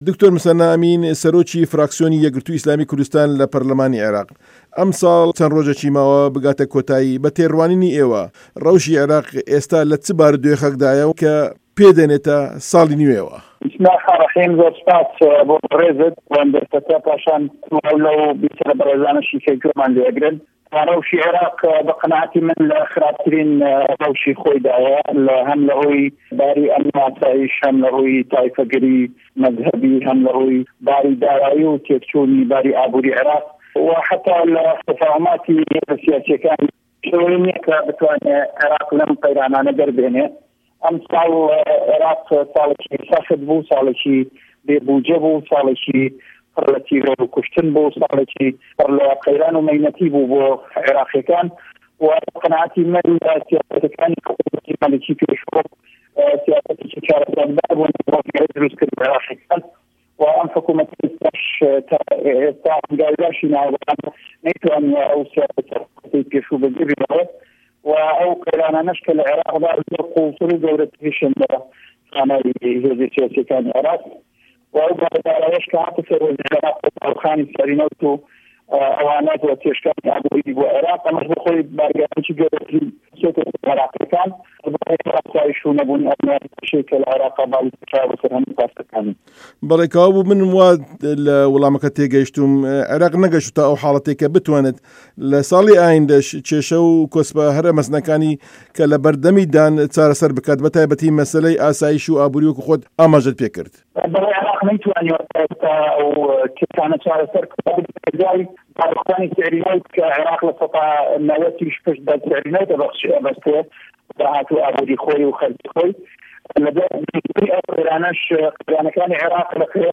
وتووێژی موسه‌نا ئه‌مین